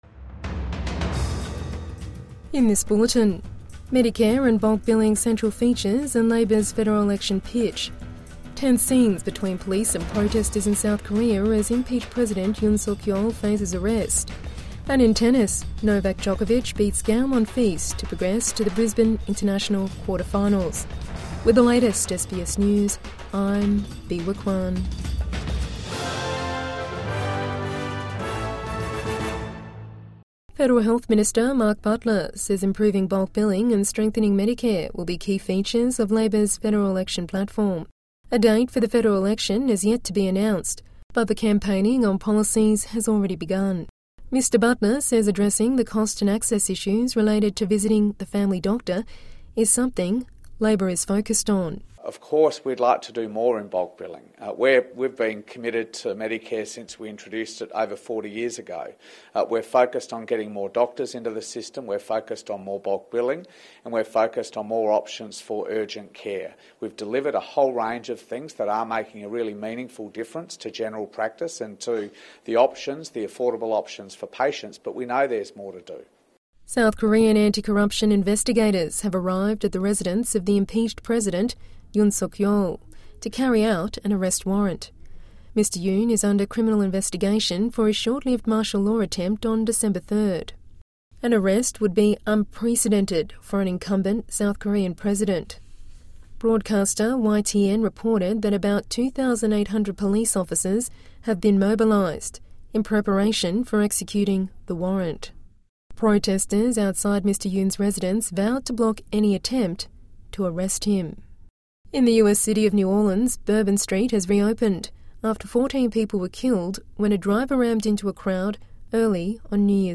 Midday News Bulletin 3 January 2025